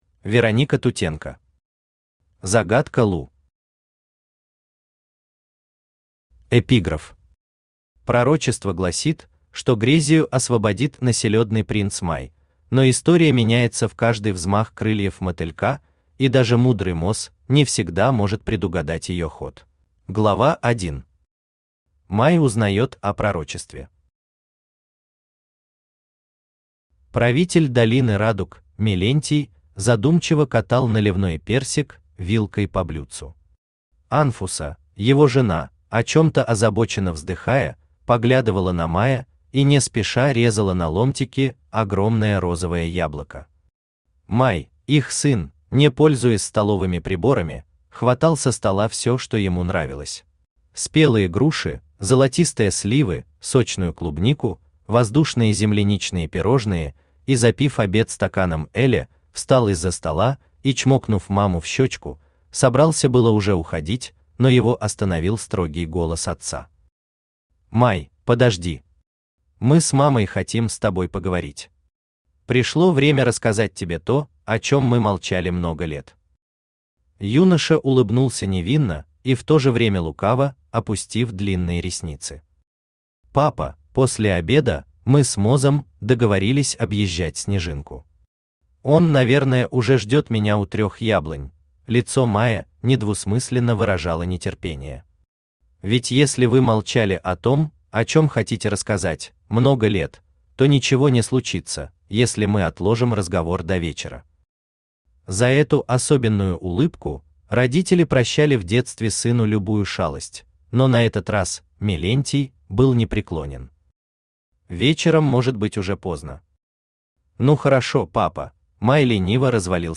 Аудиокнига Загадка Лу | Библиотека аудиокниг
Aудиокнига Загадка Лу Автор Вероника Тутенко Читает аудиокнигу Авточтец ЛитРес.